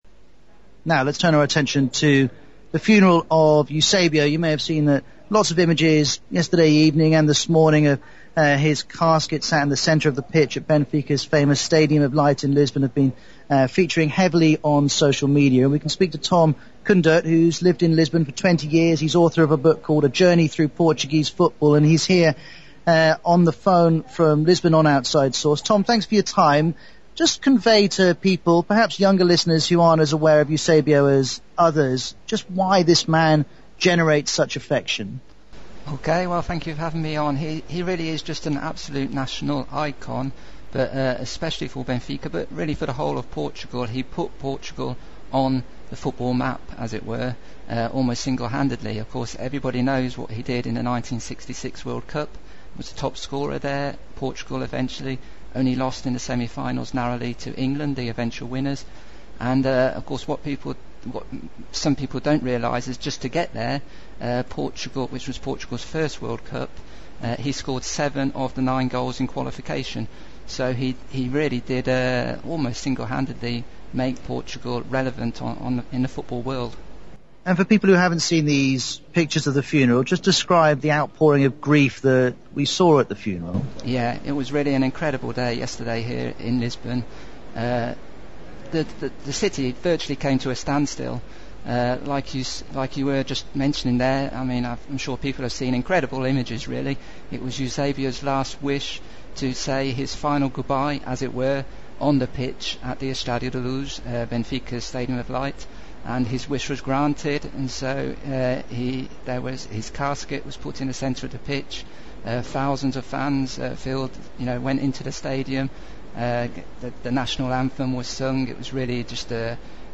Eusébio was given an emotional final farewell in Lisbon on 6 January 2014.